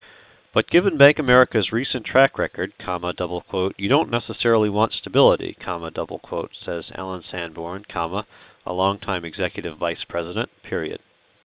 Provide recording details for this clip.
Bandlimited signal